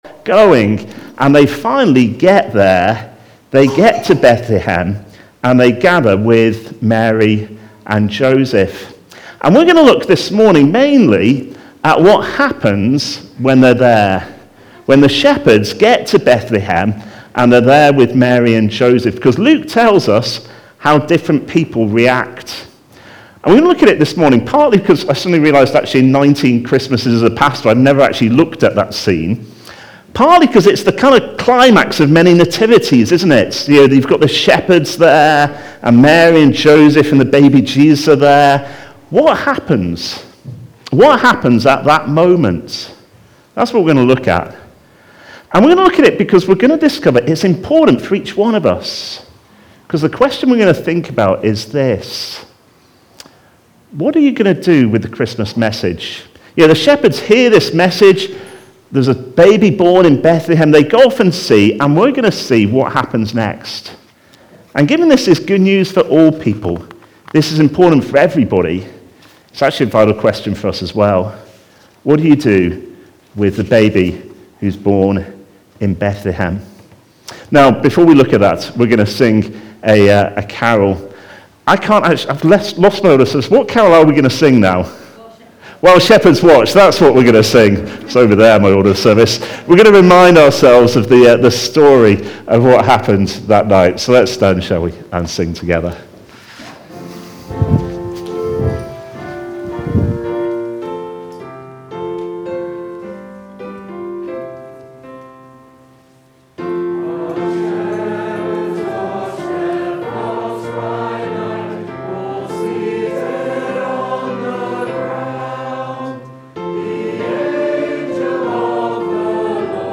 Christmas Day Service Recorded at Woodstock Road Baptist Church on 25 December 2021.